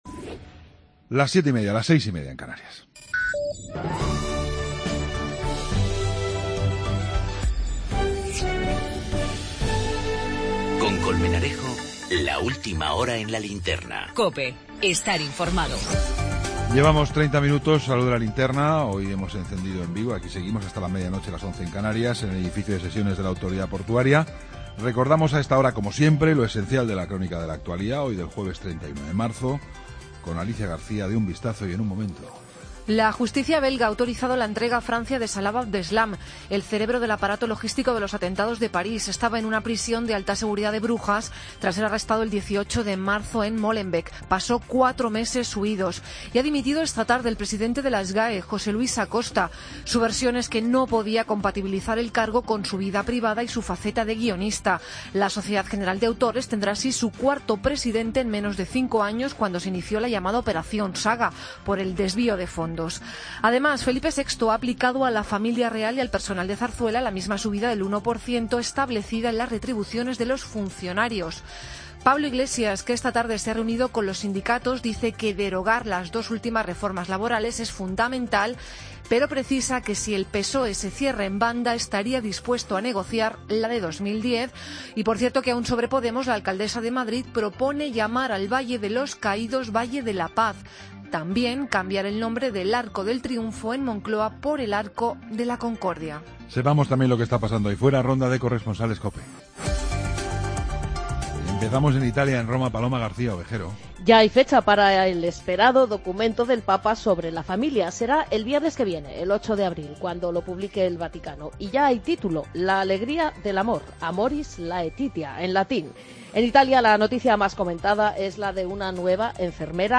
Ronda de corresponsales.
Entrevista a Teresa Pedrosa Silva, Delegada de Estado Consorcio de la Zona Franca de Vigo.